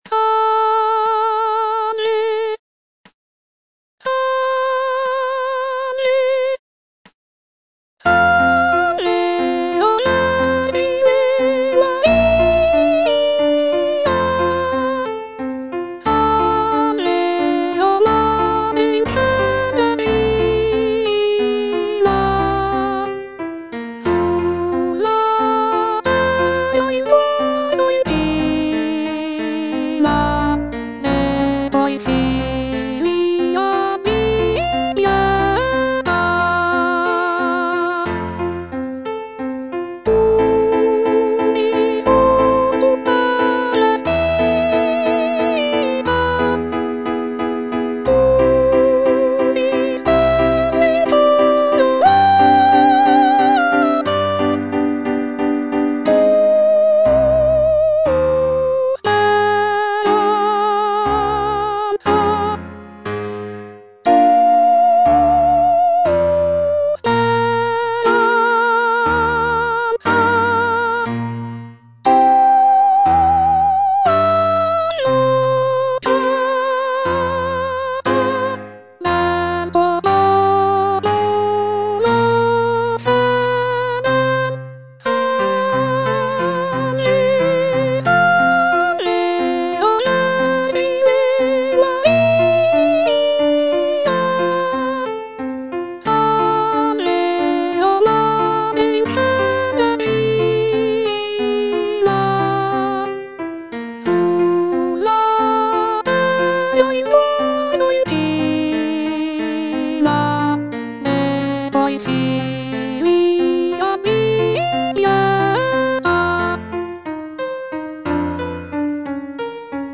G. ROSSINI - SALVE O VERGINE MARIA (EDITION 2) Soprano (Voice with metronome) Ads stop: auto-stop